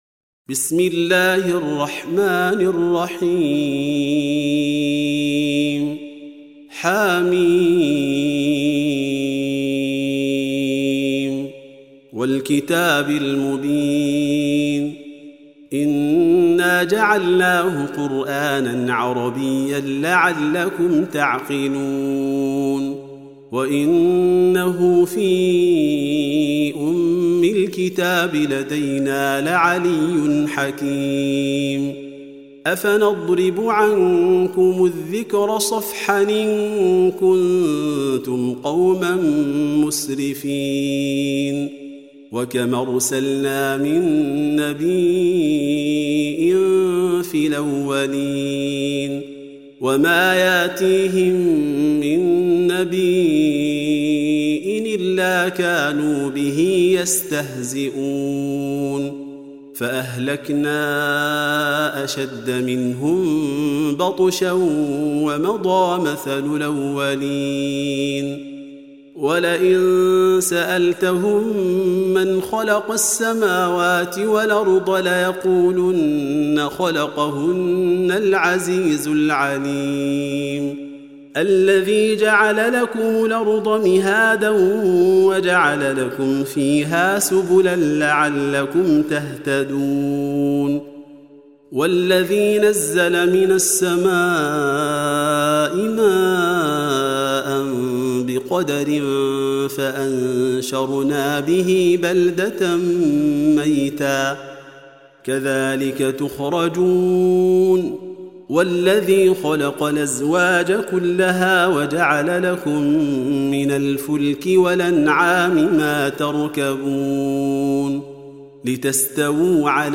Surah Repeating تكرار السورة Download Surah حمّل السورة Reciting Murattalah Audio for 43. Surah Az-Zukhruf سورة الزخرف N.B *Surah Includes Al-Basmalah Reciters Sequents تتابع التلاوات Reciters Repeats تكرار التلاوات